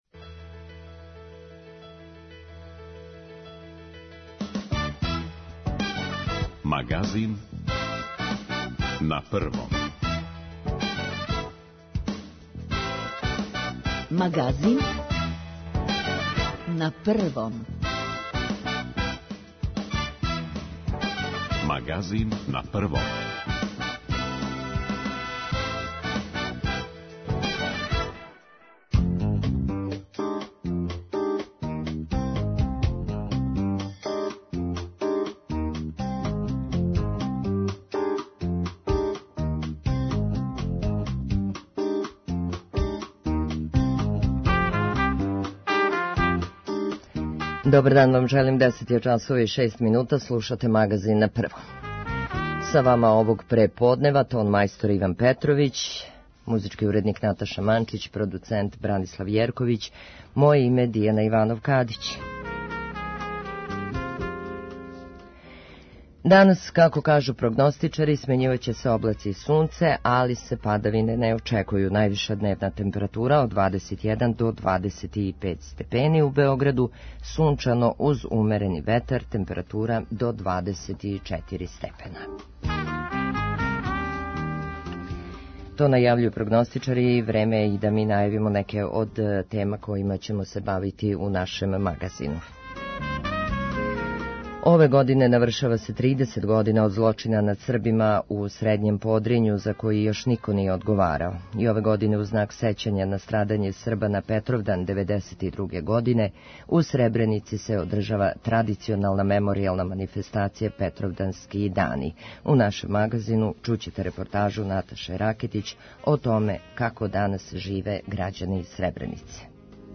Аудио подкаст Радио Београд 1